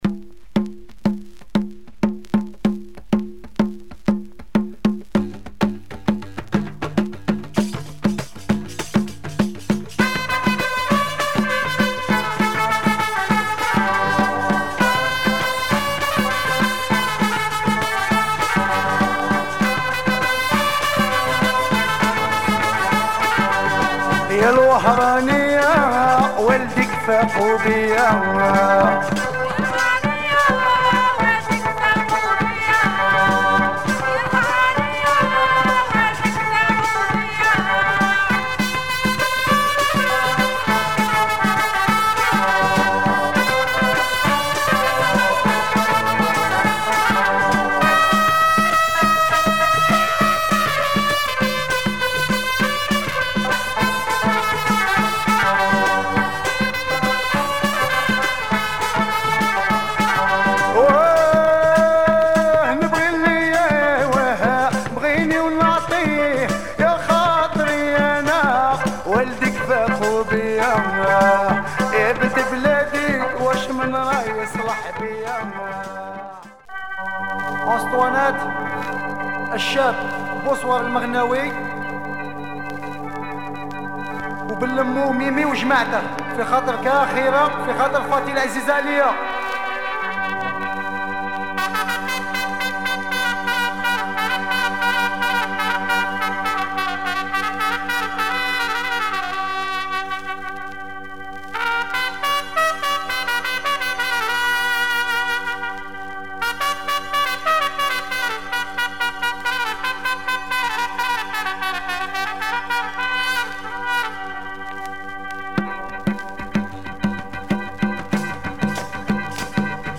Algerian proto rai
trumpet